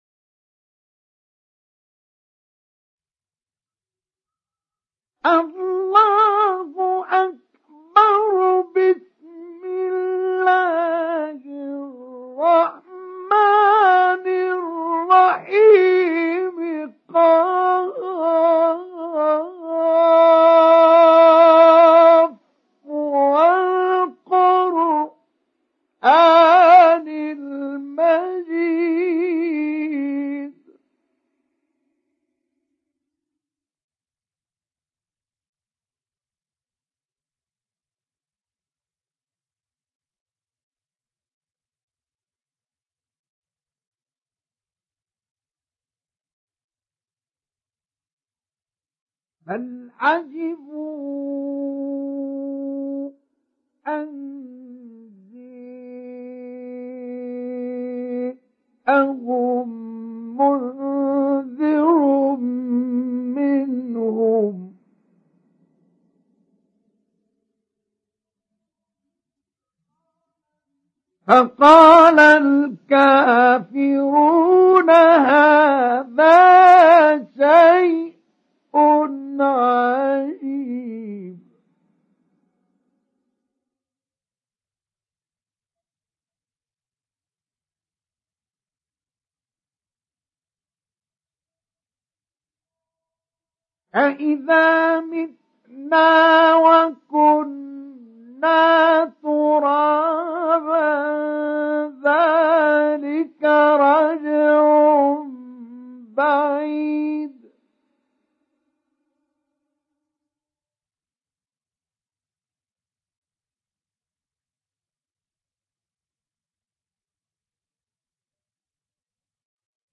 Kaf Suresi İndir mp3 Mustafa Ismail Mujawwad Riwayat Hafs an Asim, Kurani indirin ve mp3 tam doğrudan bağlantılar dinle
İndir Kaf Suresi Mustafa Ismail Mujawwad